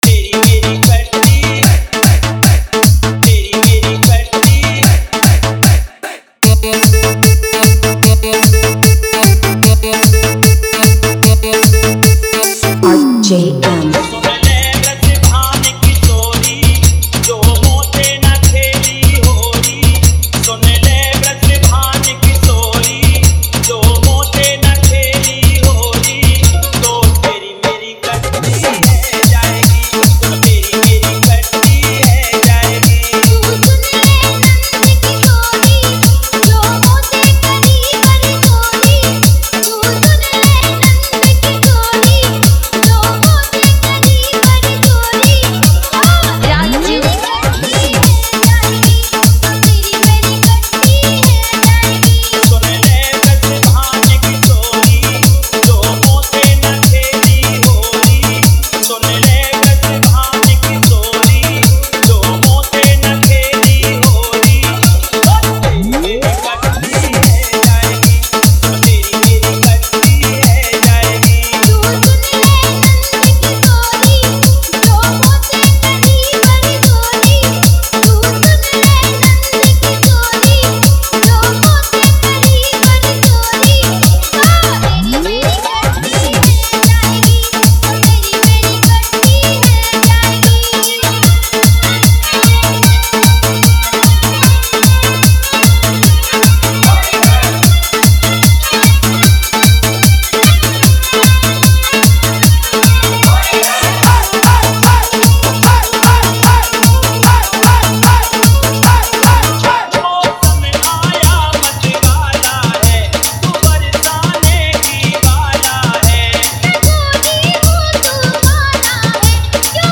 Janmashtami Special Fadu Remix
Bhakti Dance Dj Remix Mp3 Song
Radha Krishna Bhajan Remix Mp3
Fadu Dj Remix Song Download, Janmashtami Special Dj Mp3 Song